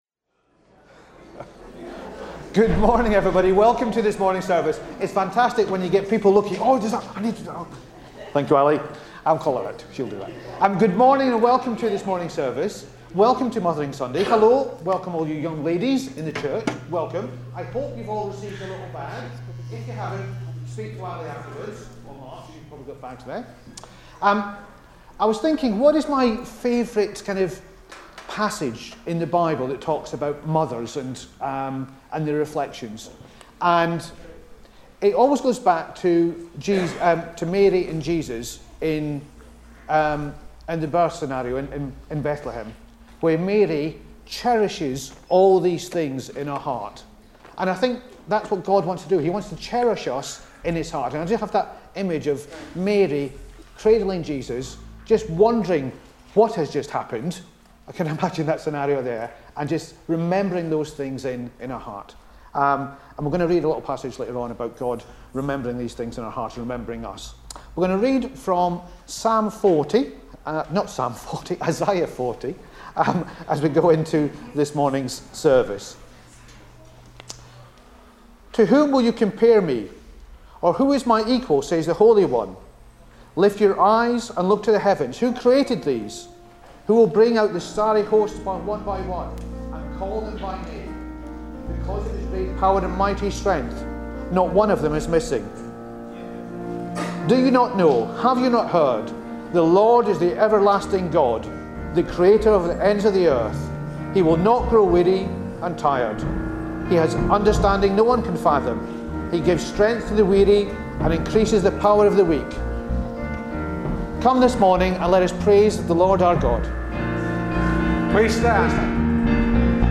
15 March 2026 – Morning Communion Service
Service Type: Morning Service